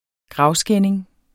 Udtale [ ˈgʁɑwˌsgεnˀeŋ ]